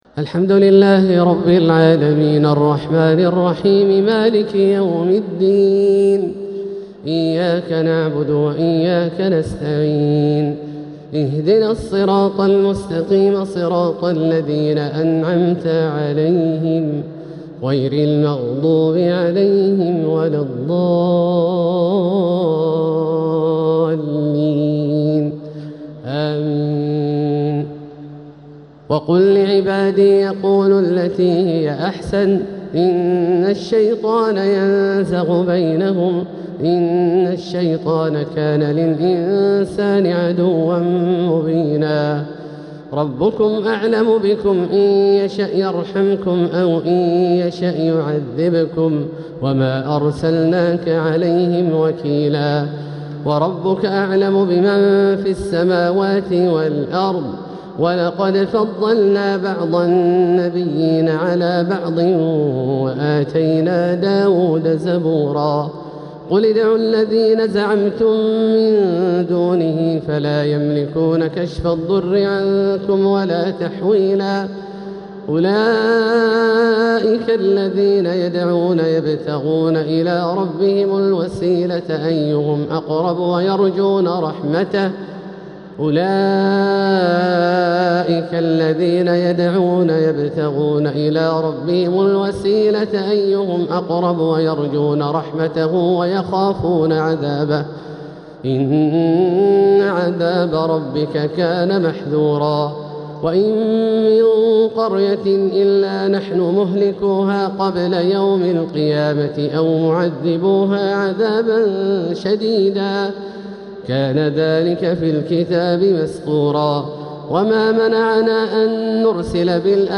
تراويح ليلة 20 رمضان 1447هـ من سورة الإسراء (53-111) | Taraweeh 20th night Ramadan1447H Surah Al-Israa > تراويح الحرم المكي عام 1447 🕋 > التراويح - تلاوات الحرمين